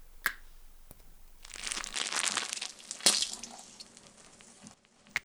administer_iv_01.ogg